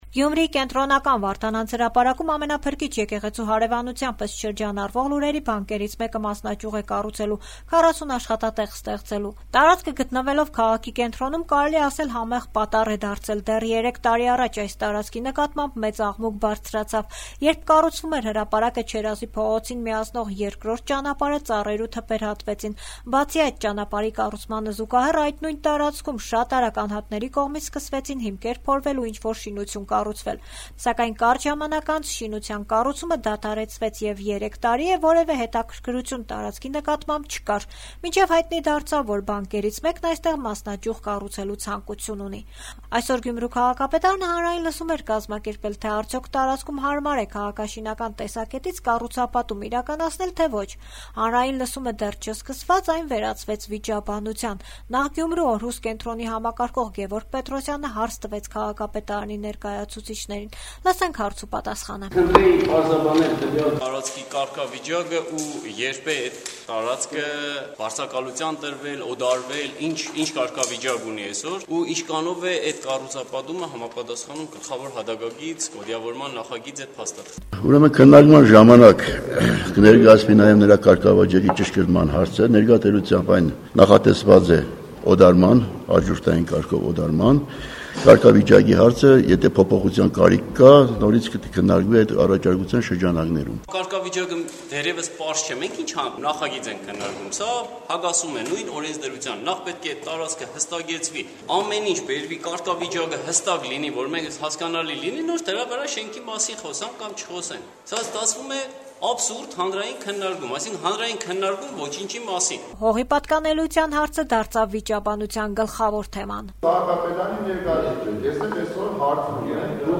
Գյումրու քաղաքապետարանում հանրային լսումները վերածվեցին վիճաբանության